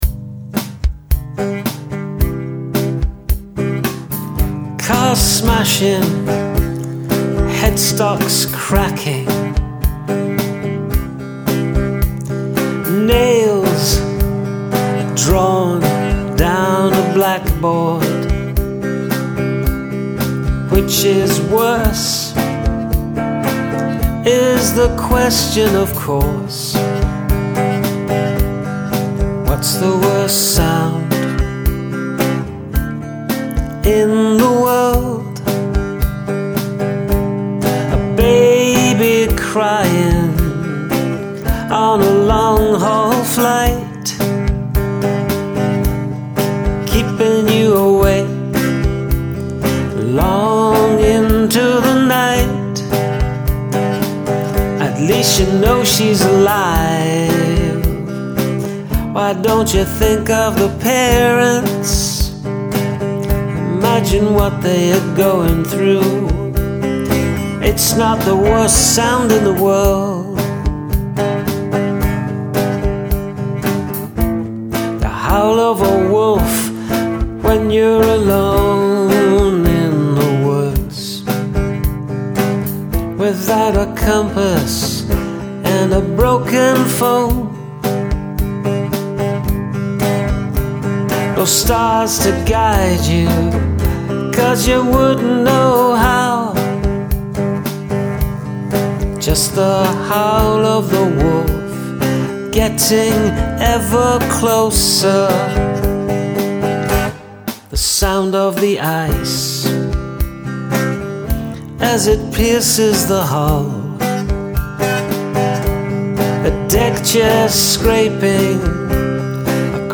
Cool melody.